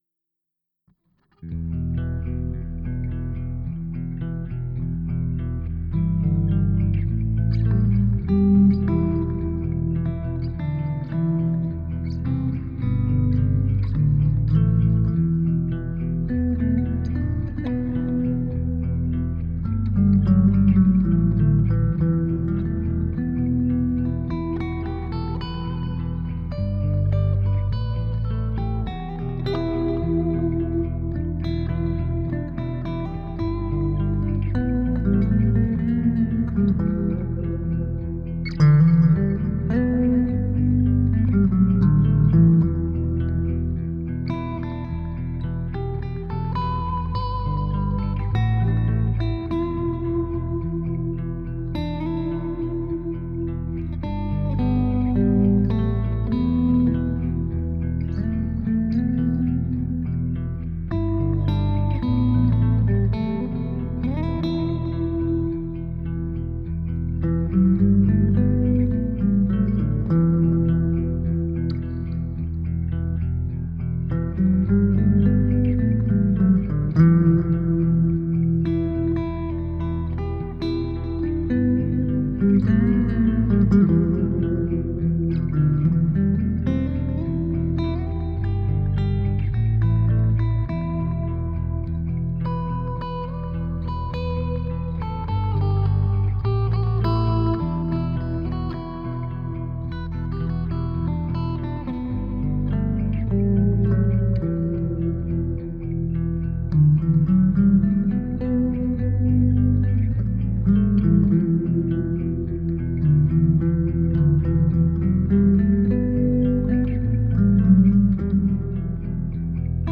Finde den Bach aber sehr beruhigend Sollte ich den im Mittelteil leiser machen ???
Ist an sich ne Improvisation.....
ok mal im Mittelteil den Bach was leiser gemacht...